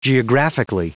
Prononciation du mot geographically en anglais (fichier audio)
Prononciation du mot : geographically